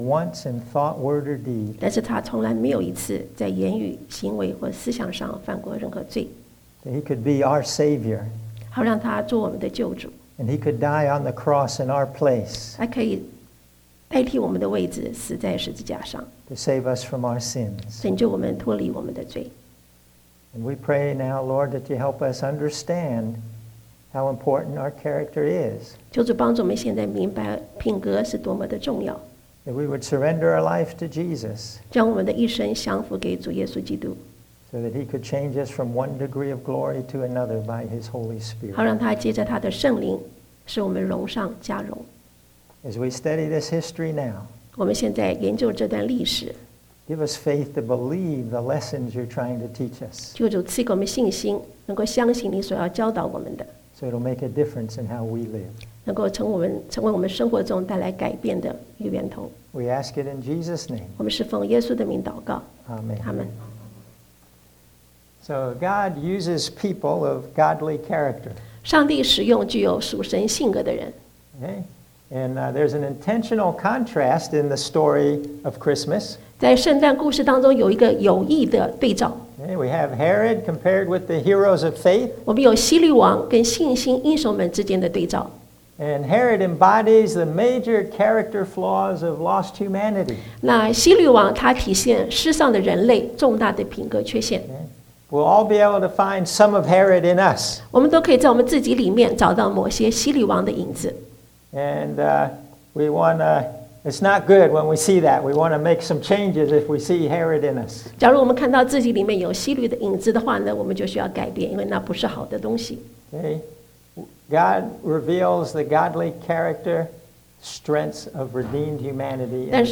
Bilingual Sermon